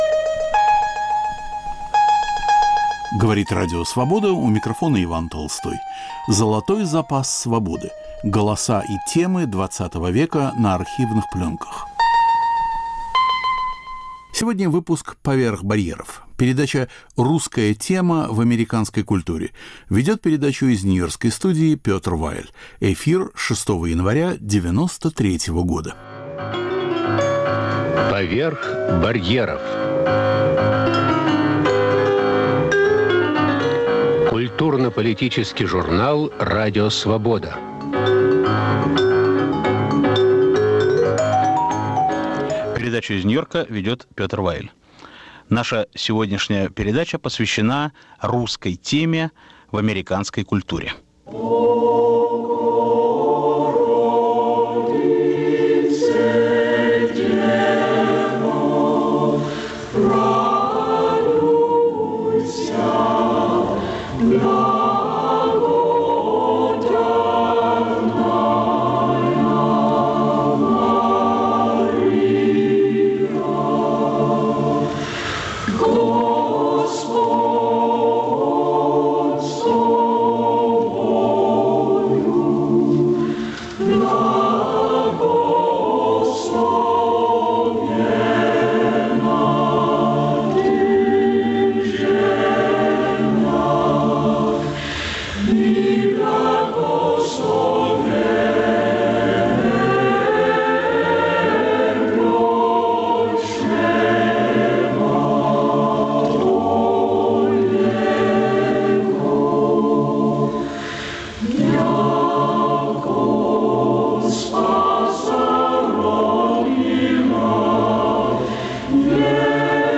Православная духовная музыка Игоря Стравинского, книги с русской тематикой, вручение президентской награды Мстиславу Ростроповичу. Участвует Соломон Волков. Ведущий Петр Вайль.